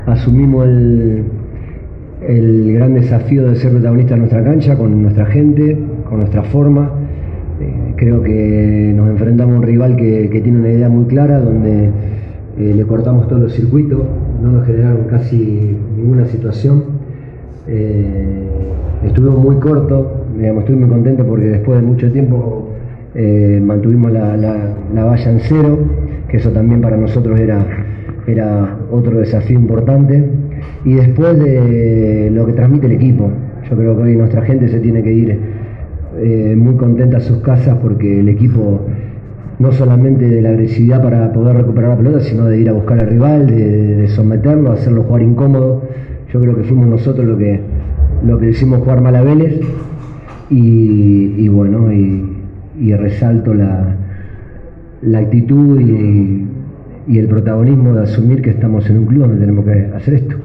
Luego que Central venciera 1 a 0 a Vélez en el Gigante de Arroyito, el entrenador técnico, Cristian González, brindó una conferencia de prensa y analizó el rendimiento del equipo.